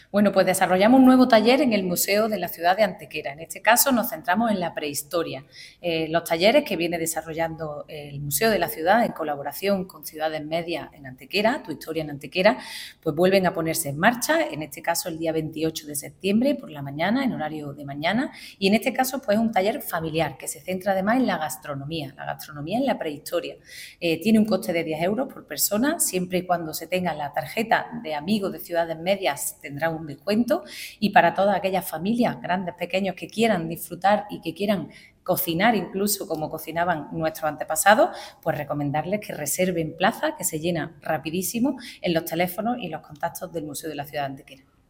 La teniente de alcalde delegada de Turismo, Ana Cebrián, informa del desarrollo de un taller de carácter familiar en el Museo de la Ciudad de Antequera (MVCA) centrado en la Prehistoria y enfocado en su gastronomía.
Cortes de voz